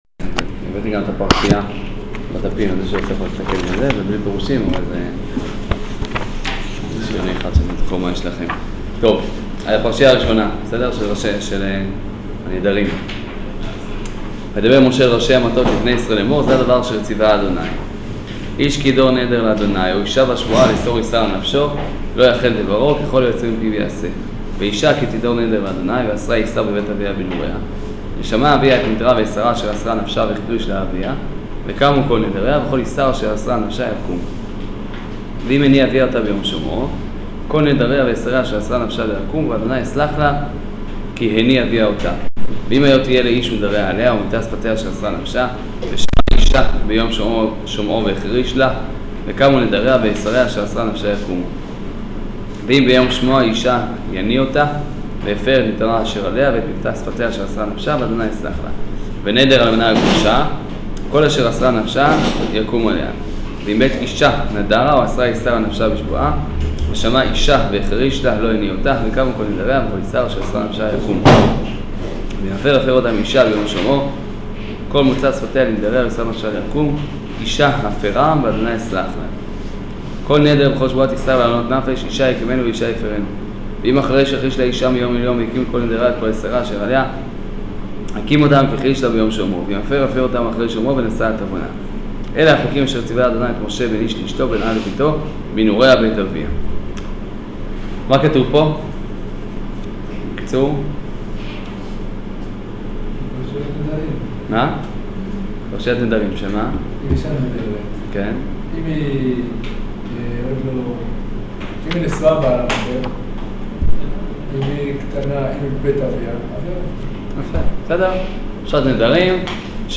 שיעור פרשת מטות מסעי